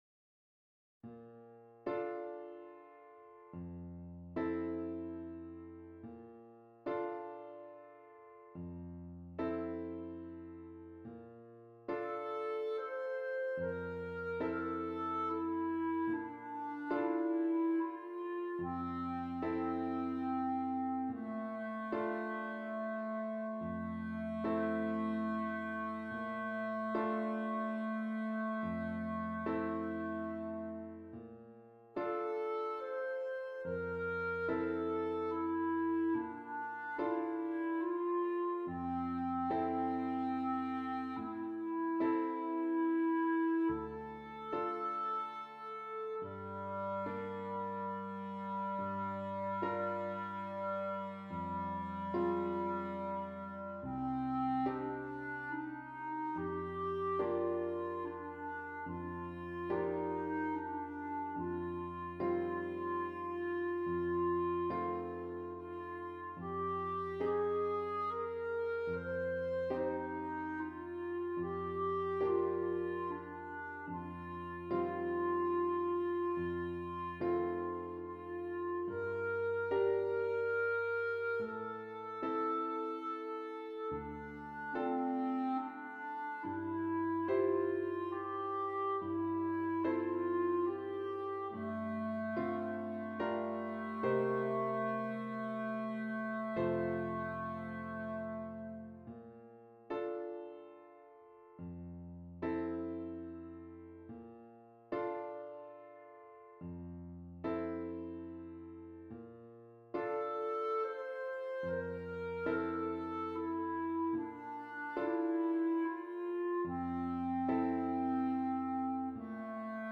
Clarinet and Keyboard
quiet minimal pieces